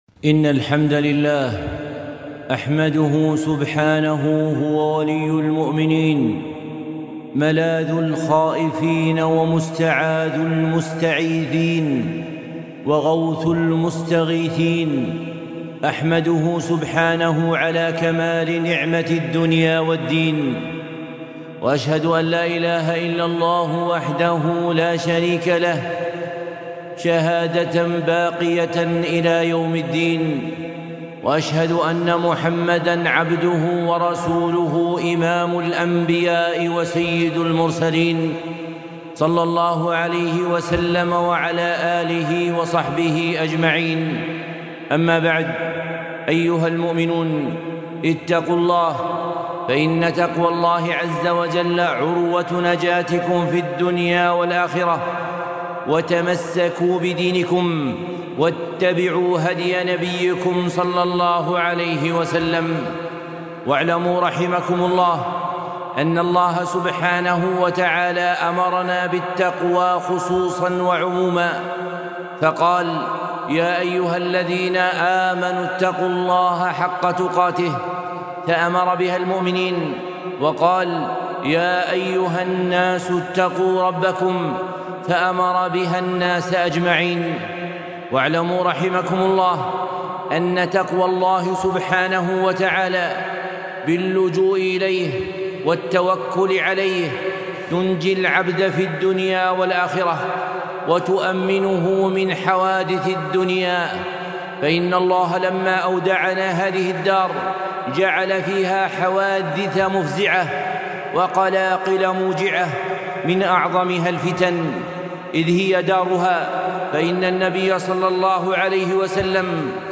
خطبة - الحصون من المسيح الدجال